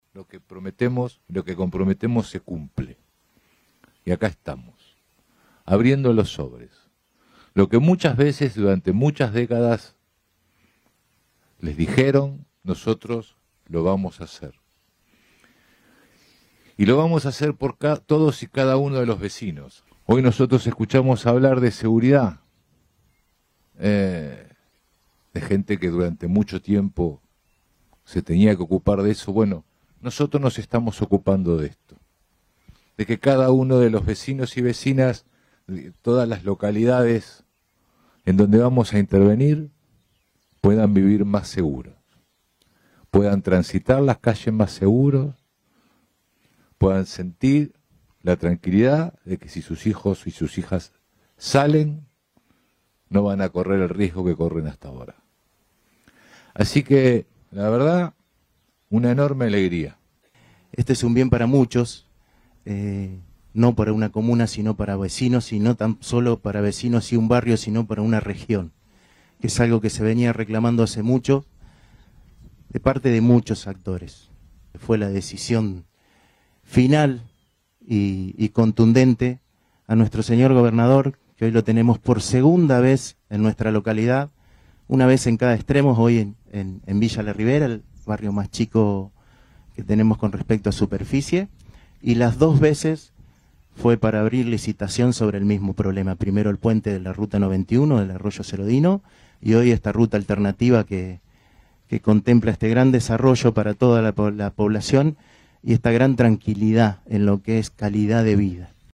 Declaraciones Corach y Abraham